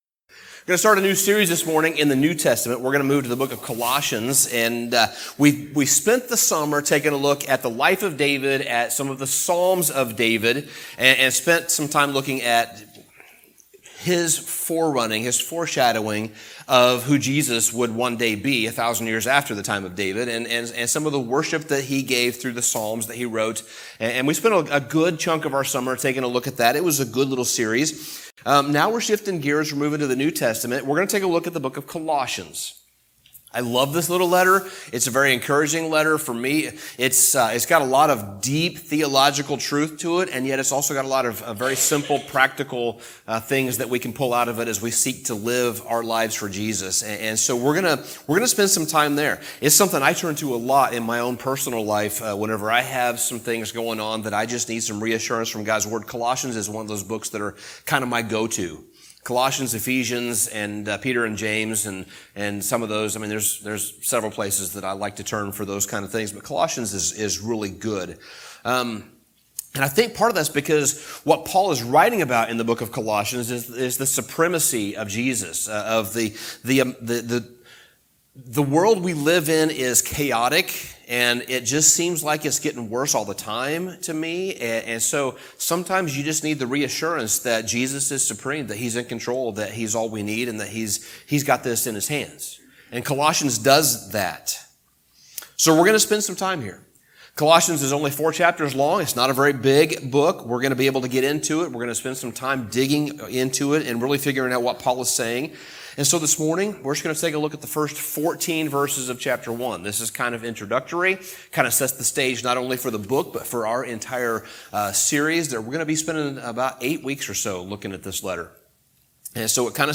Sermon Summary Colossians 1:1-14 provides both an introduction of Paul and Timothy to the Colossian believers, and also an introduction to the content and themes of his letter to them.